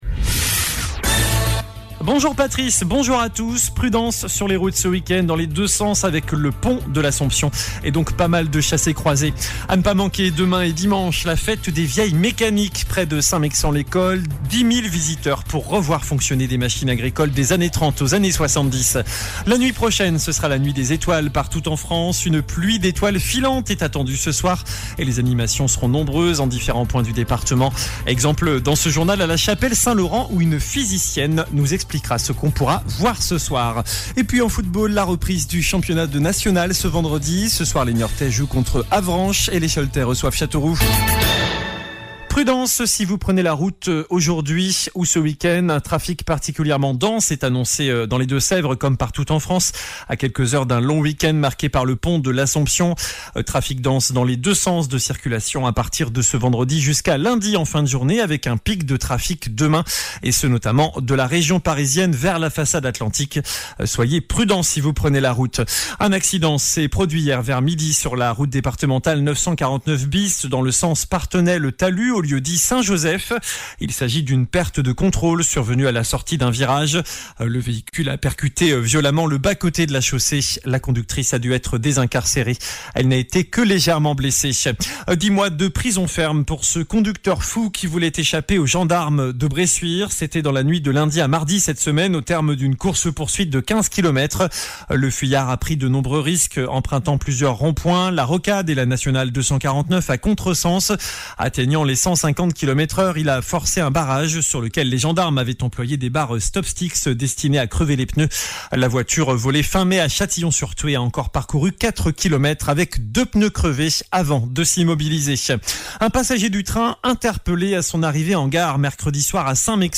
JOURNAL DU VENDREDI 11 AOÛT ( midi )